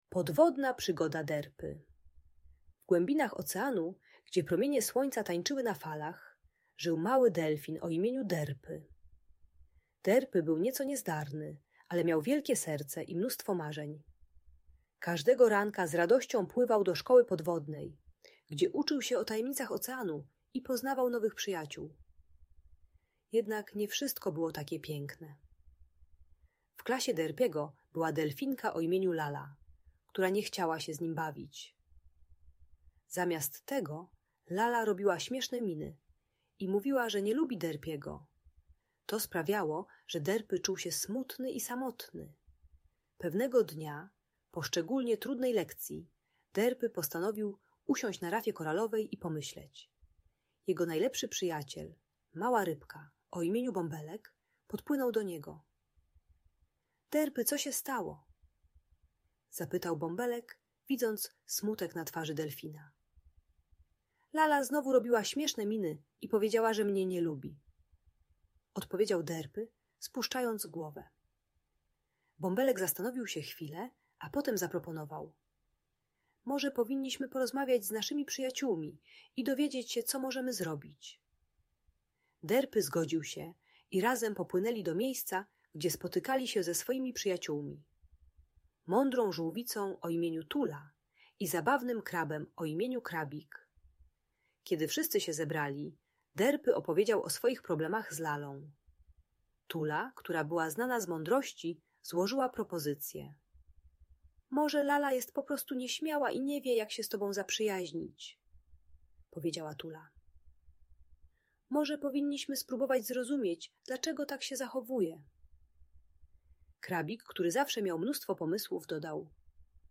Podwodna przygoda Derpy - Lęk wycofanie | Audiobajka